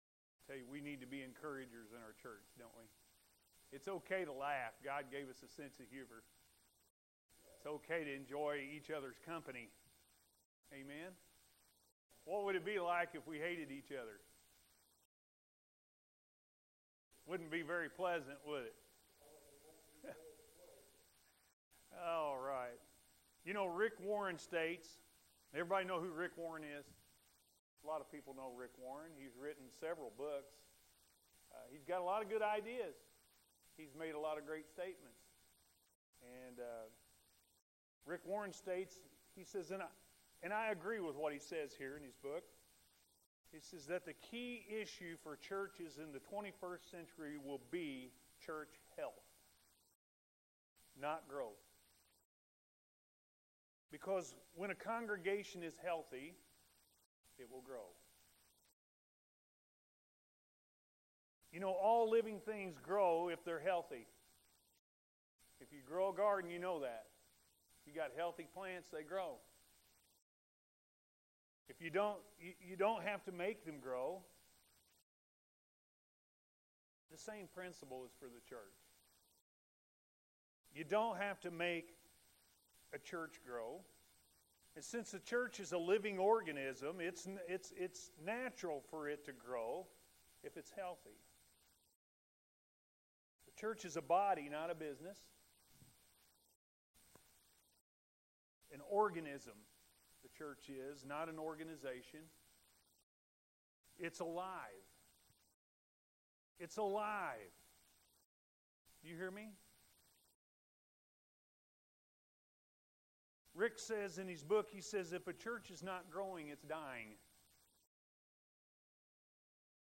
What Is Your Passion?-A.M. Service